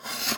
scrapes1.ogg